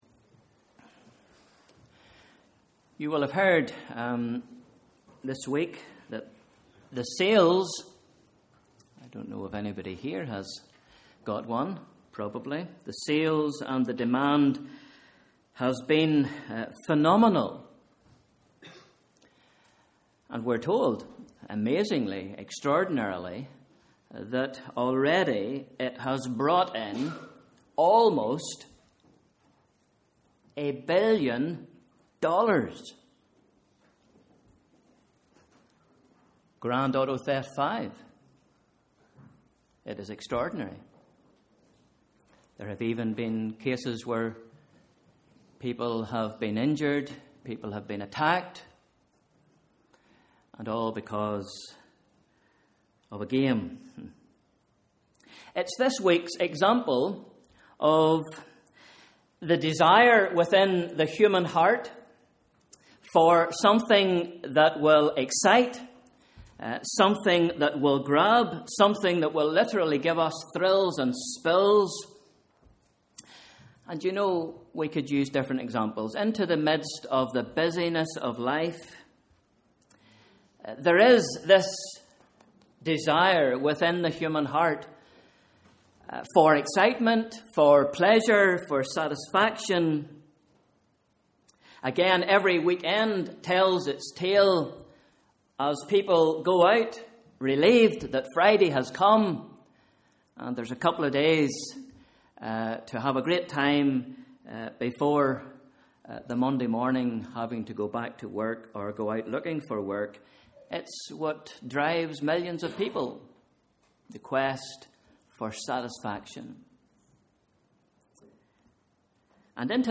Morning service: Sunday 22nd September 2013 / Bible Reading: Isaiah 55 v 1-7